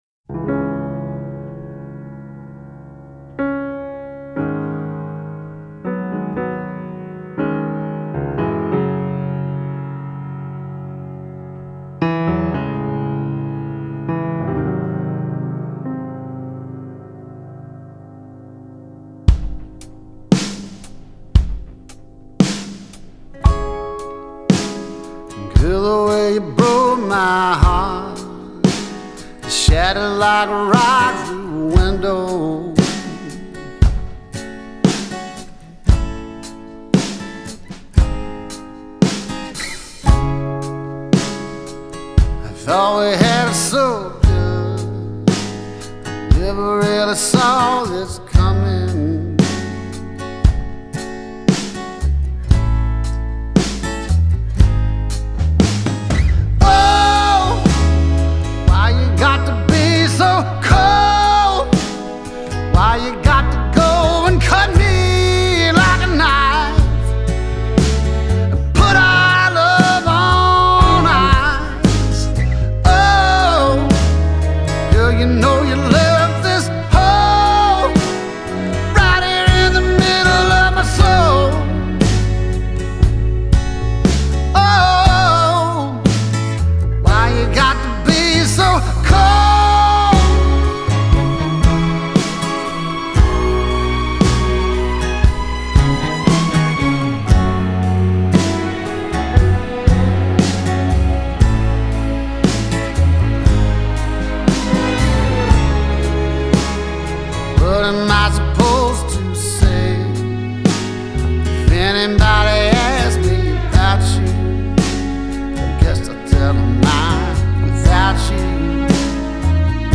унаследованный от корней кантри-музыки.